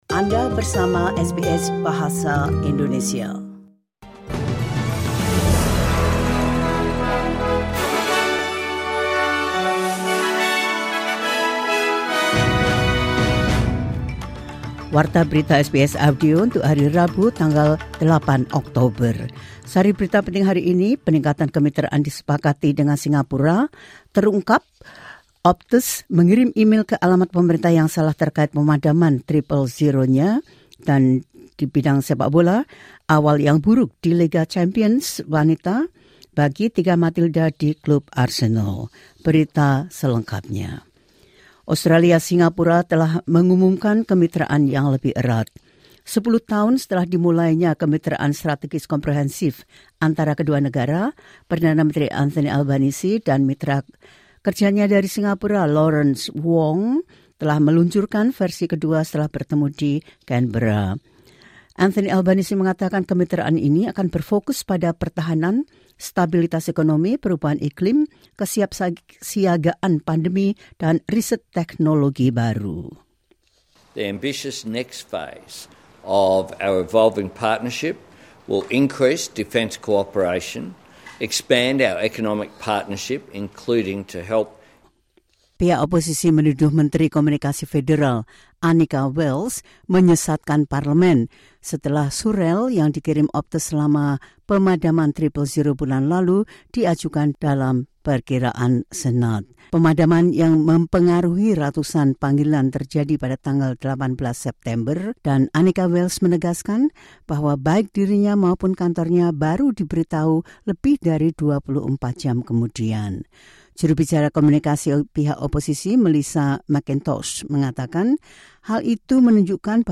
The latest news SBS Audio Indonesian Program – 08 October 2025.